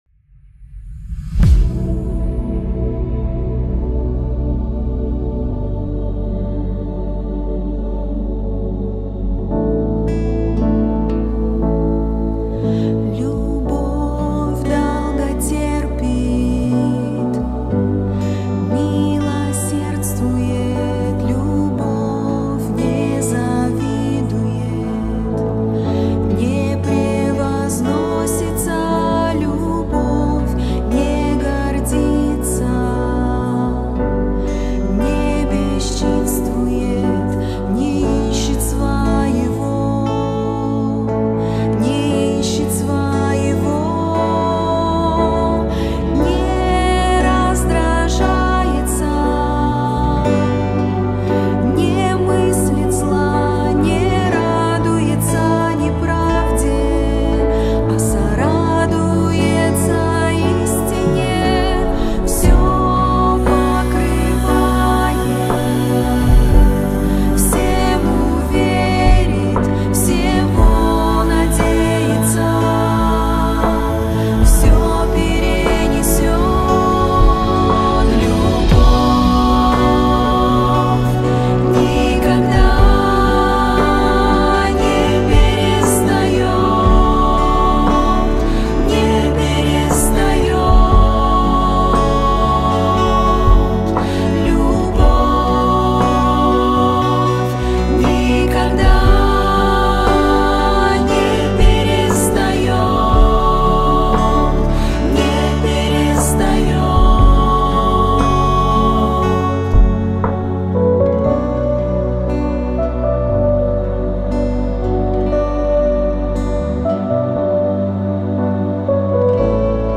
578 просмотров 576 прослушиваний 83 скачивания BPM: 120